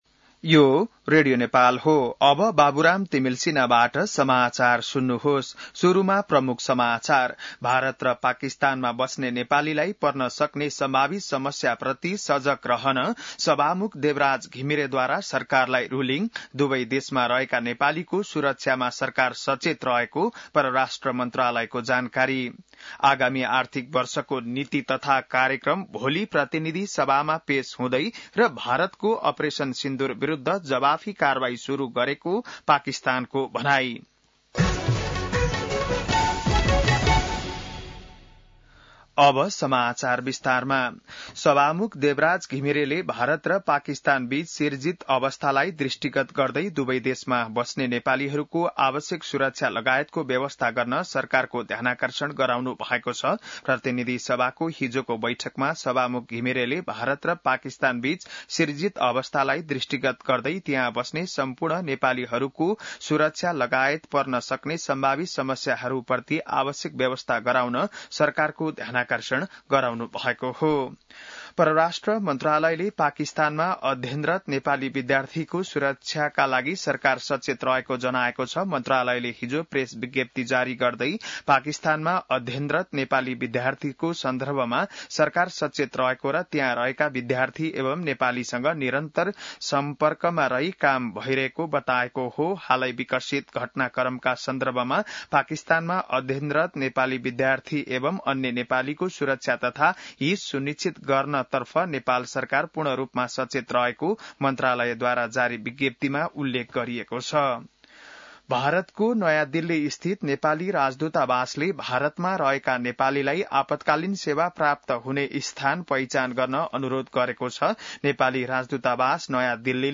बिहान ९ बजेको नेपाली समाचार : २७ वैशाख , २०८२